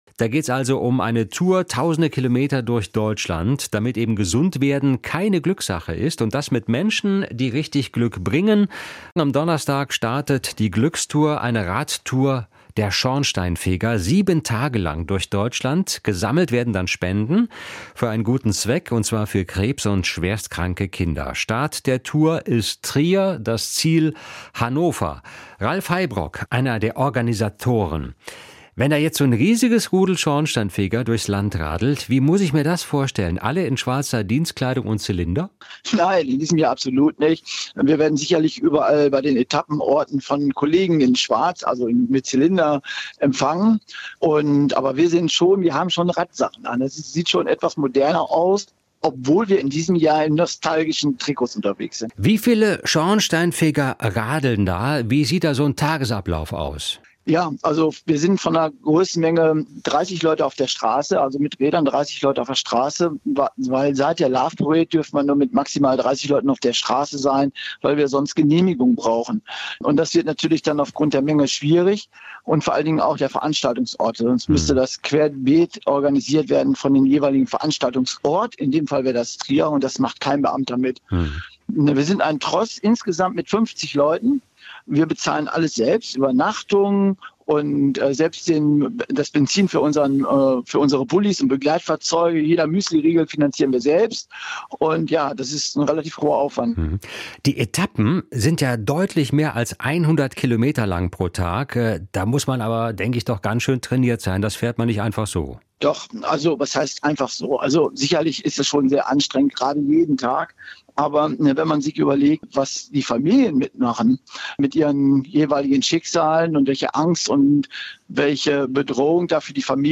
Mehr Interviews